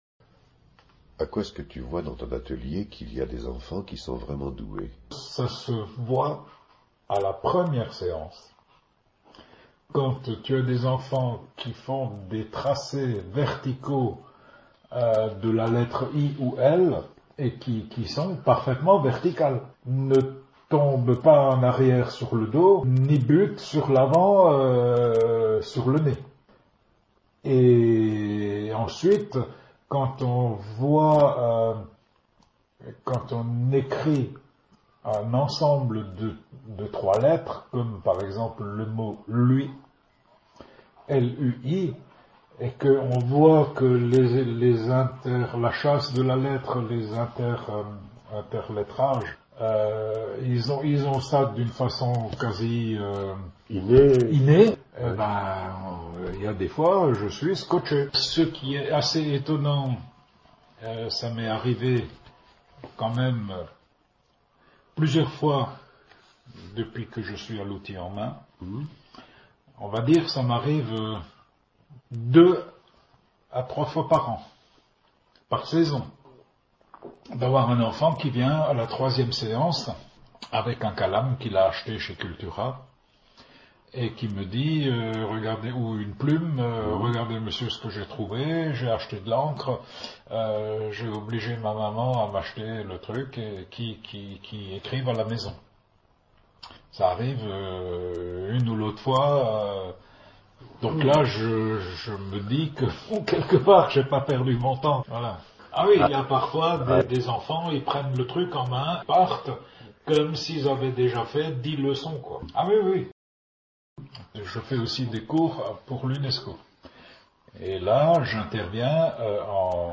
Cliquez sur le lecteur ci-dessous pour entendre la voix passionnée de notre calamiste :